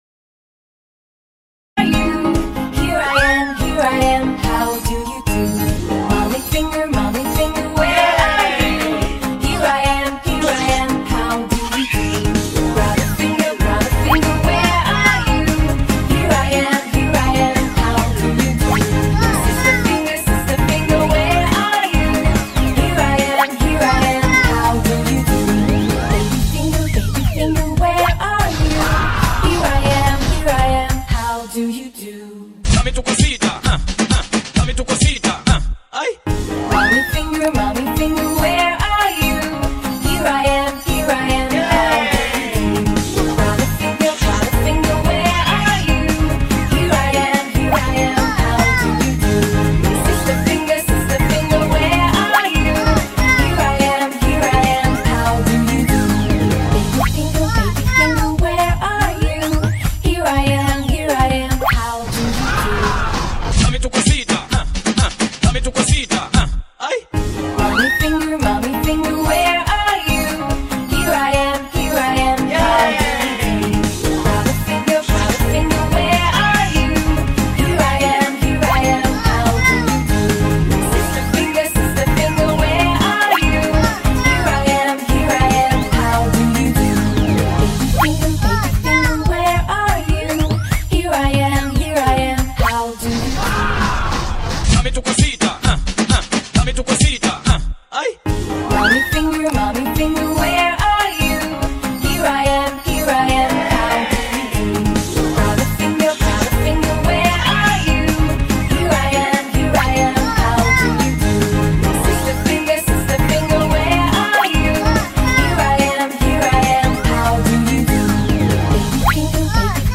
Alien Peppa Pig Cartoon Wrong sound effects free download